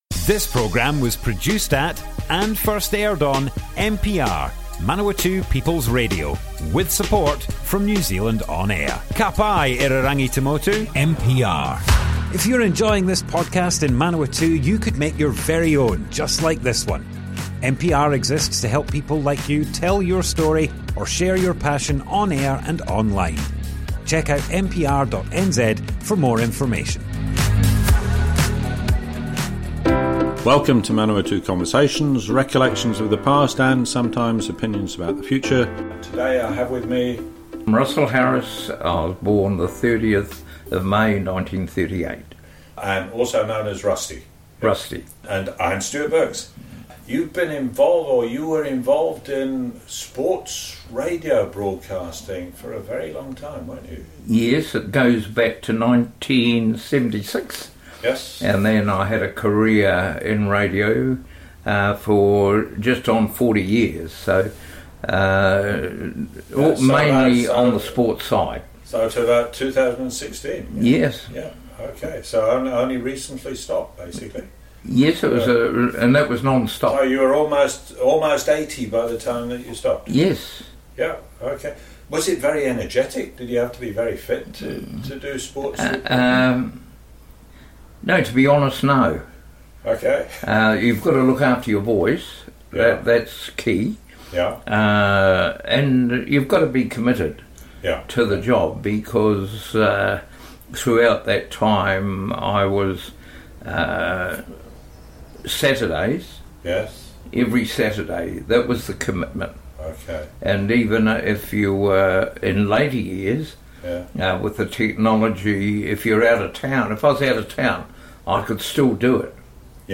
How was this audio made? Manawatu Conversations More Info → Description Broadcast on Manawatu People's Radio, 12th December 2023, Part 1 of 2. Work as radio sports broadcaster 1976–2016.